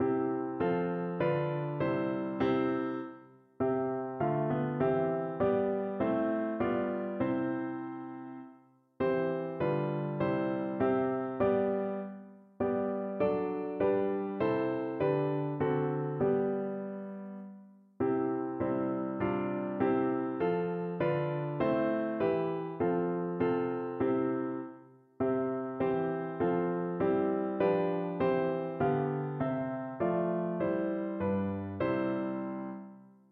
Notensatz 1 (4 Stimmen gemischt)